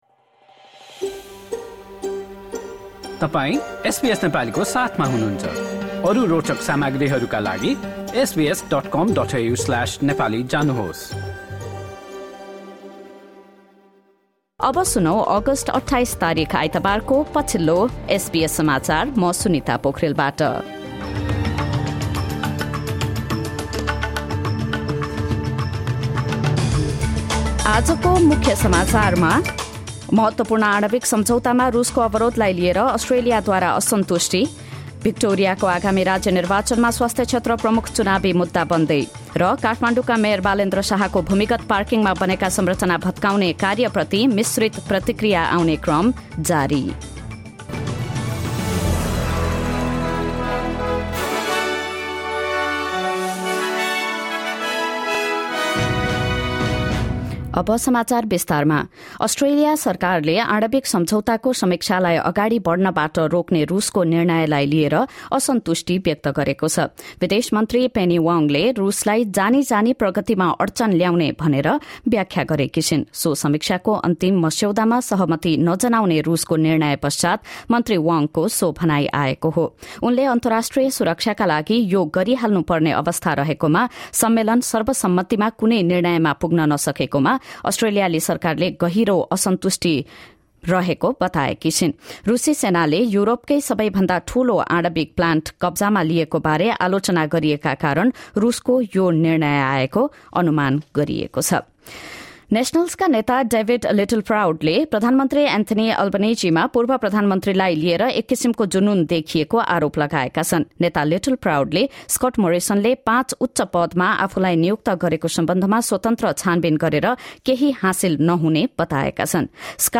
एसबीएस नेपाली अस्ट्रेलिया समाचार: आइतबार २८ अगस्ट २०२२